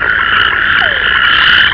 Frog Site: The Wild Ones Format: AU - 0.020MB Description: Frog Chorus in a pond in Miradores del Mar, in the state of Veracruz, Mexico If necessary, please download latest versions of QuickTime , RealOne Player , or Windows Media .
frogChorus.au